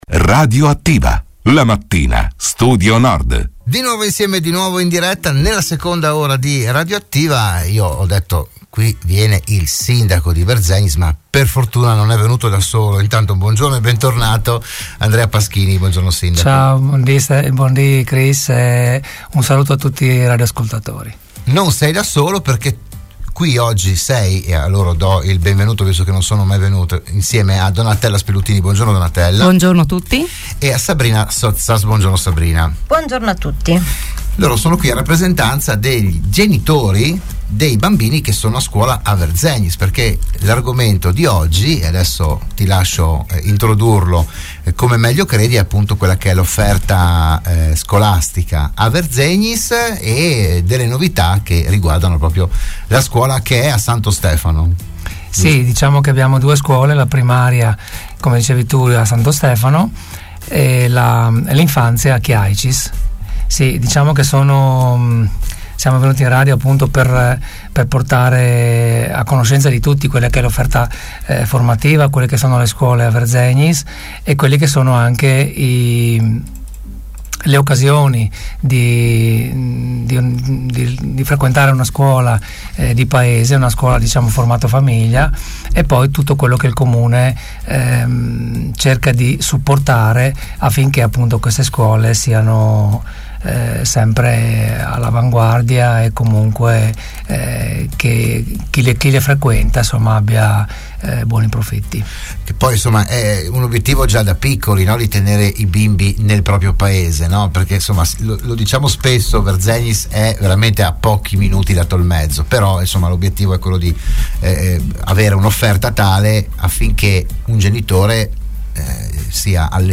Ne hanno parlato a Radio Studio Nord il sindaco Andrea Paschini